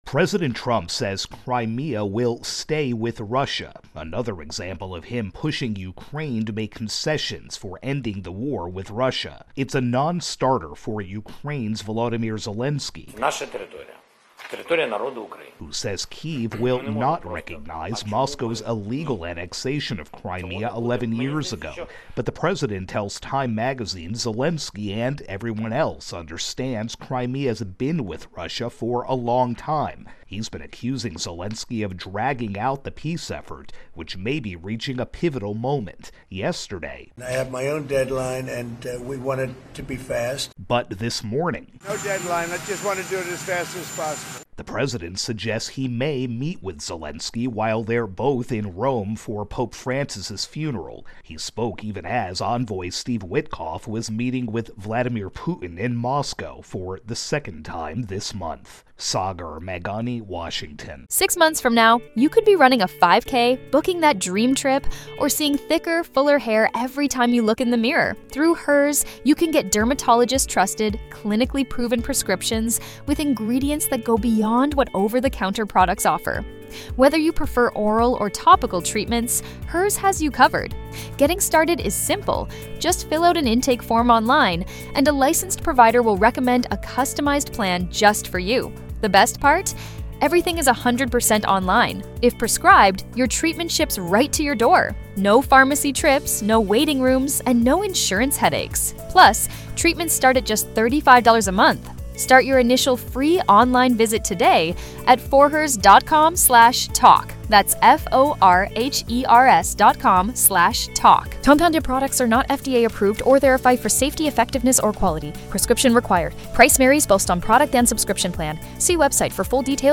reports on President Trump saying Russia will keep Crimea as part of any peace deal with Ukraine.